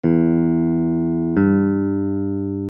In the diagrams below, we are jumping from a note on an open string (any string will do) to another note on the same string.
Minor Third = 1 ½ steps
minor-3rd.mp3